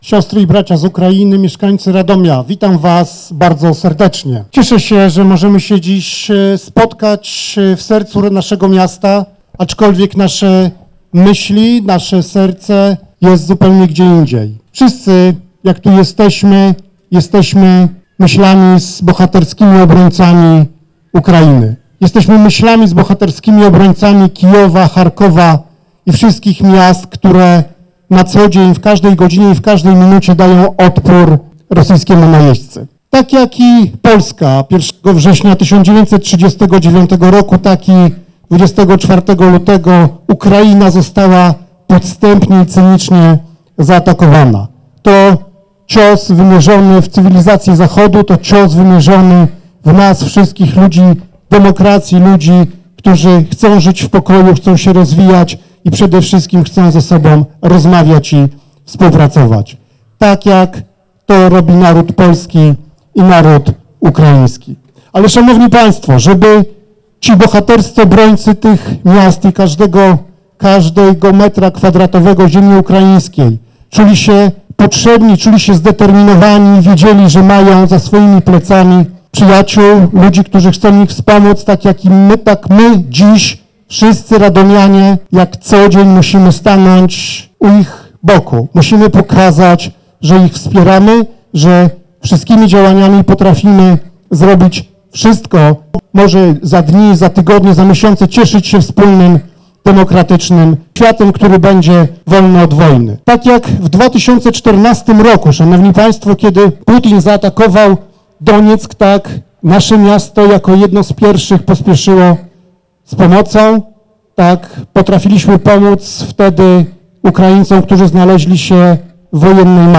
Tłumy mieszkańców wyraziły wczoraj swoje wsparcie dla narodu ukraińskiego na Placu Corazziego
Radomianie dla Demokracji i radomska Platforma Obywatelska zorganizowali Wiec Solidarności z Ukrainą.
Mówi prezydent, Radosław Witkowski: